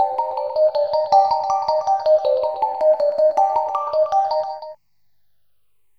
Ambient / Keys / SYNTH042_AMBNT_160_C_SC3.wav